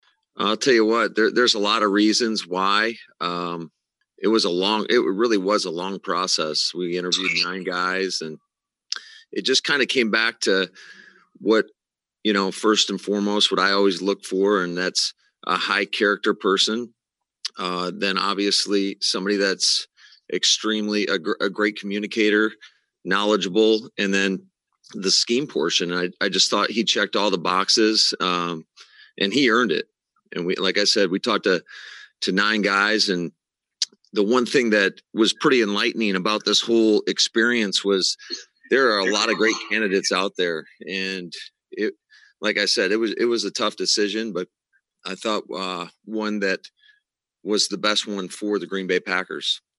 For the first time since the inglorious NFC Championship game exit at the hands of the Tampa Bay Bucaneers in January, Green Bay Packers Head Coach Matt LaFleur went before the zoom camera at Lambeau Field today.